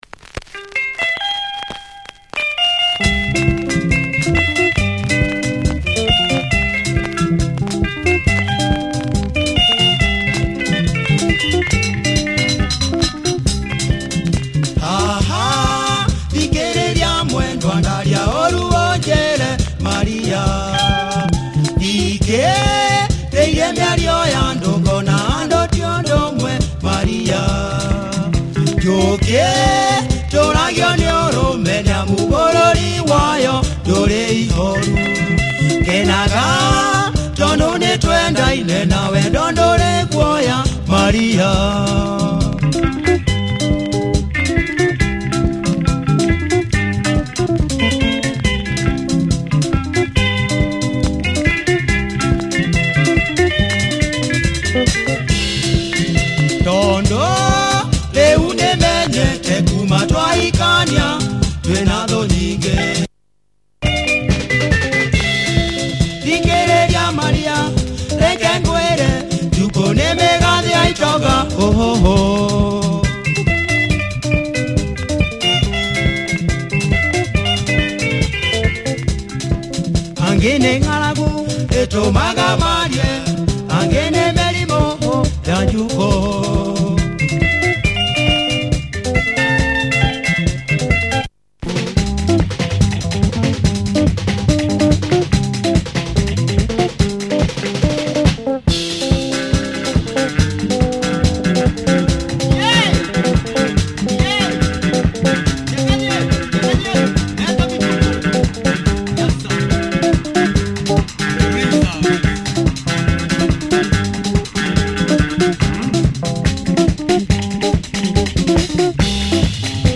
Nice punchy Kikuyu Benga, check the breakdown.
Club friendly groove!